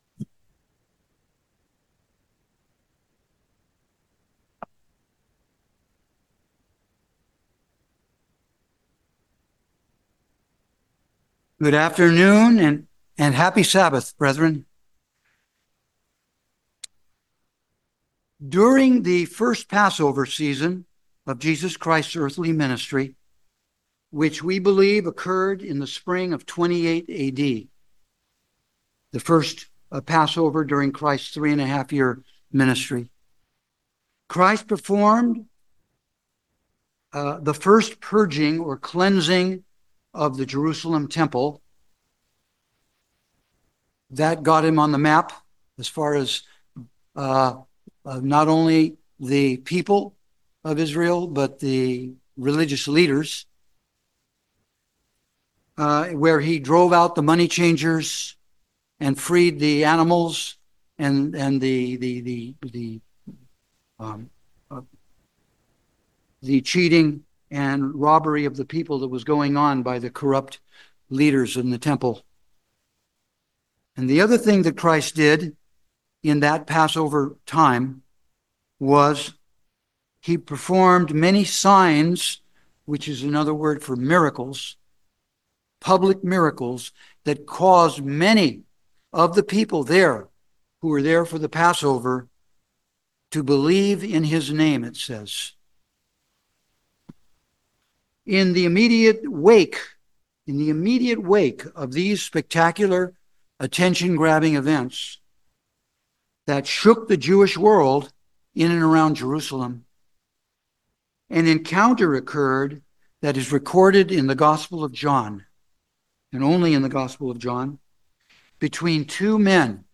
Sermons
Given in San Francisco Bay Area, CA San Jose, CA Petaluma, CA